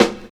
100 SNARE 3.wav